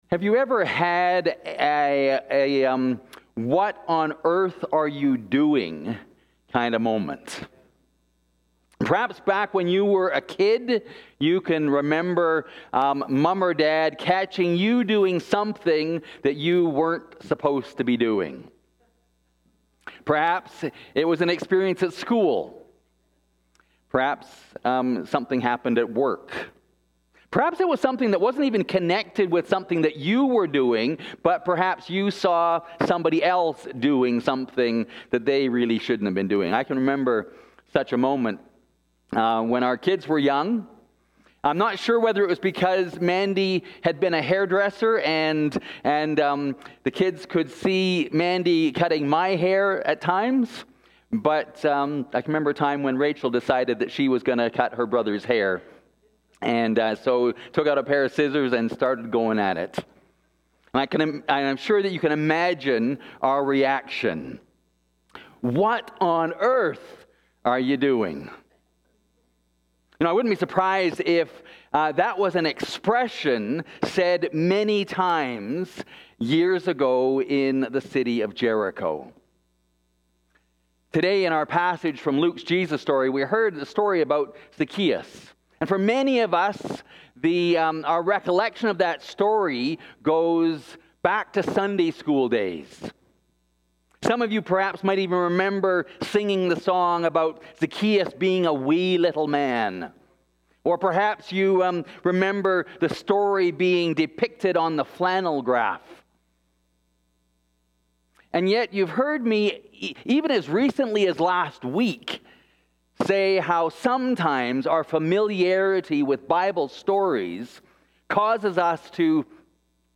Luke 19:1-10 Click here to view the sermon questions.